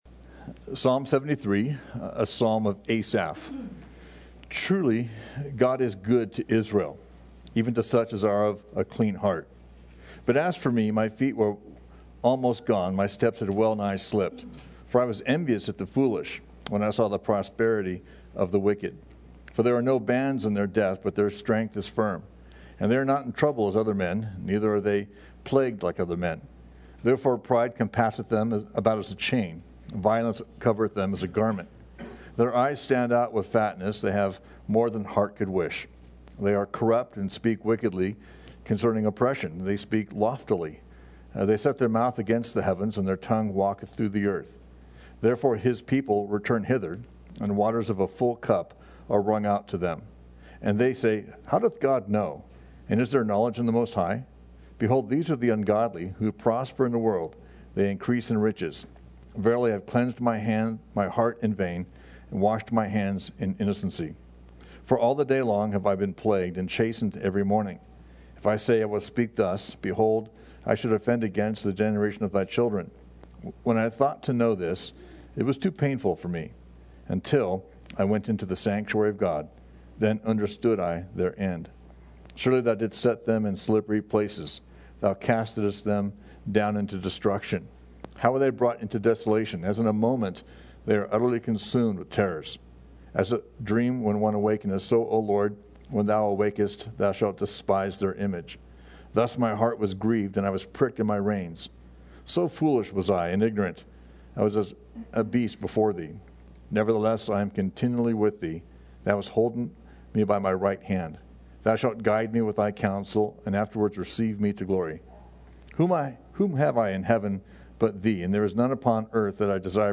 is teaching through the Bible on Sunday mornings and Wednesday nights.